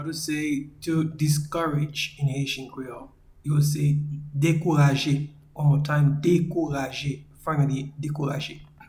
Pronunciation and Transcript:
to-Discourage-in-Haitian-Creole-Dekouraje.mp3